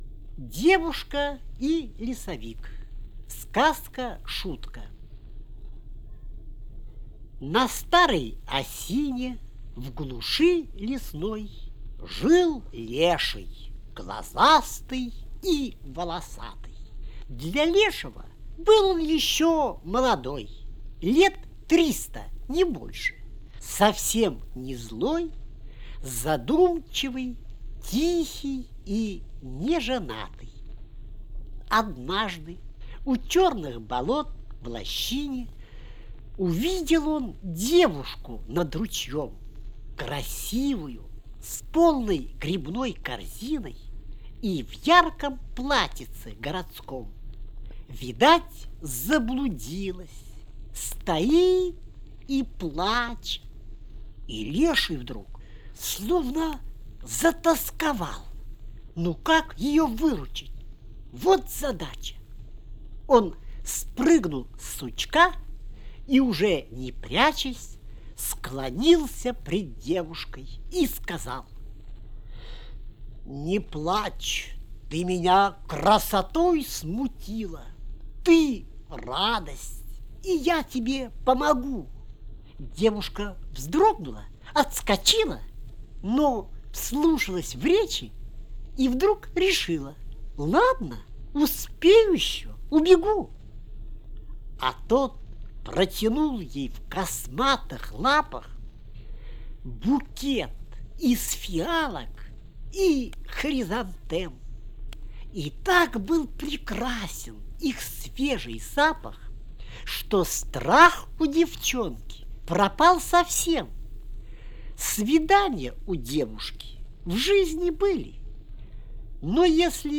4. «Эдуард Асадов – Девушка и лесовик (Чит. Автор)» /
Asadov-Devushka-i-lesovik-Chit.-Avtor-stih-club-ru.mp3